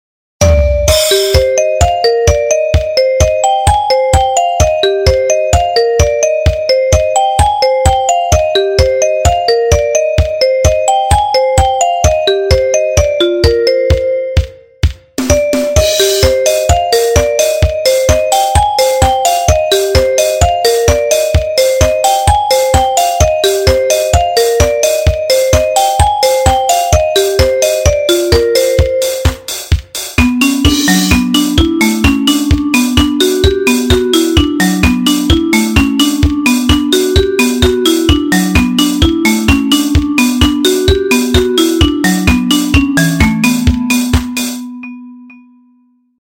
Marble music